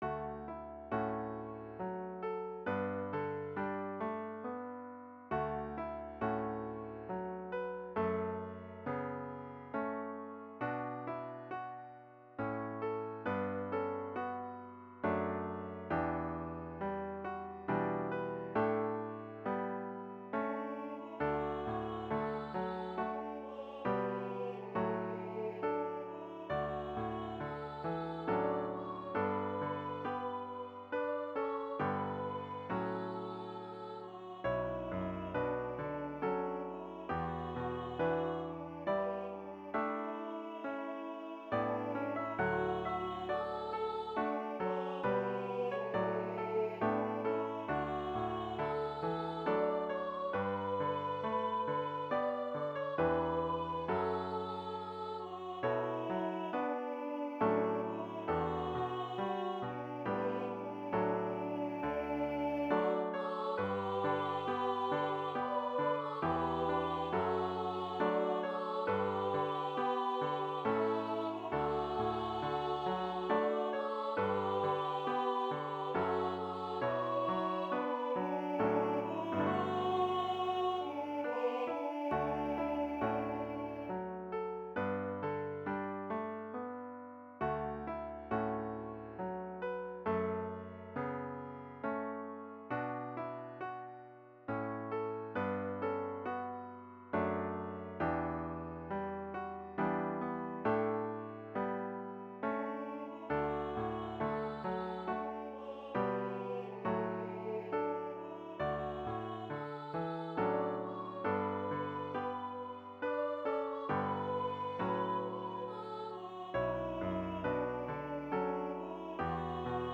Voicing/Instrumentation: 2 part choir , Duet